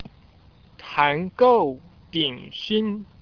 Start (High Speed Internet Only: mouse click the sentence number to hear its pronunciation in standard Chinese)